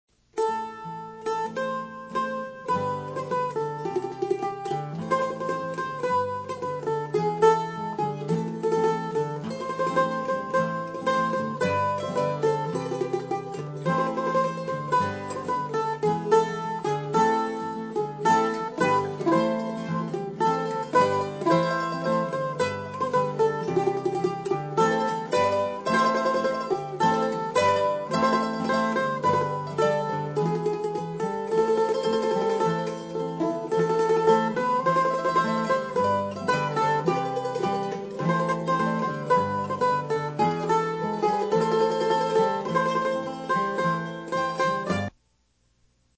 Lead Mandolin
Harmony Mandolin
Guitar